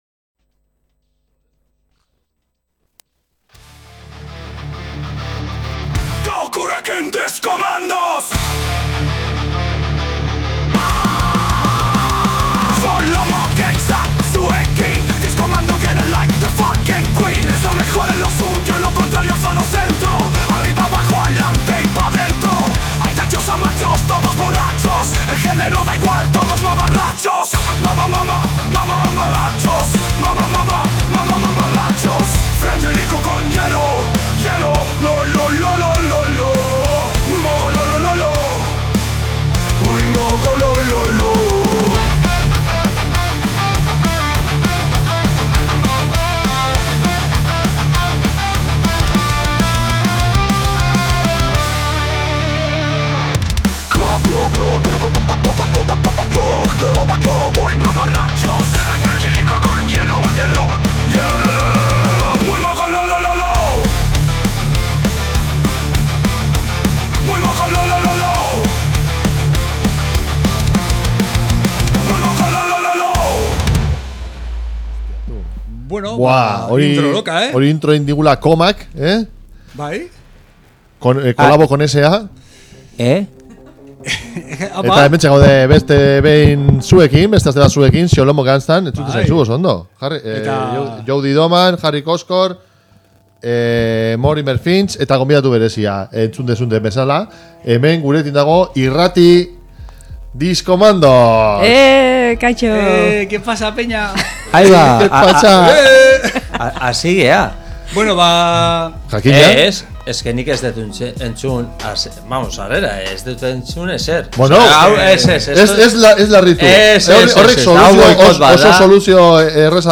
Mundu osoko Rapa, entrebistak zuzenean, Bass doinuak eta txorrada izugarriak izango dituzue entzungai saio honetan.